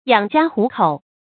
養家糊口 注音： ㄧㄤˇ ㄐㄧㄚ ㄏㄨˊ ㄎㄡˇ 讀音讀法： 意思解釋： 謂勉強養活家人，使不餓肚。